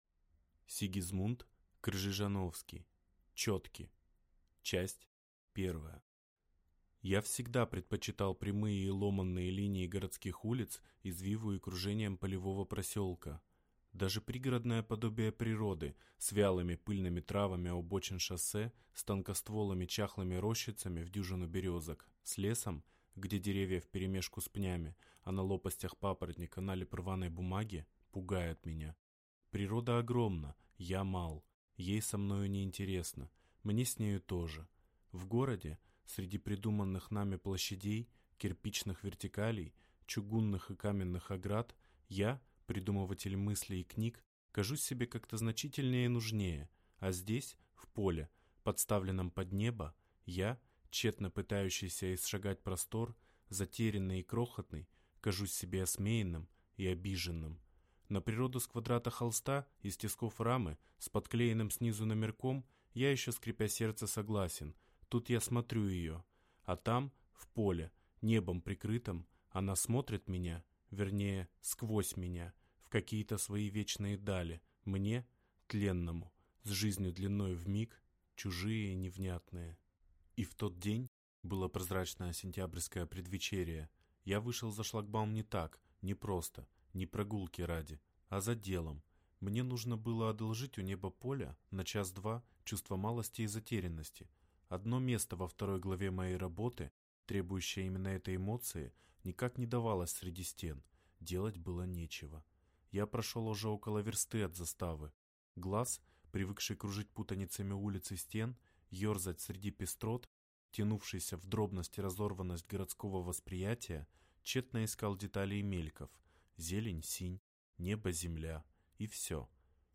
Аудиокнига Четки | Библиотека аудиокниг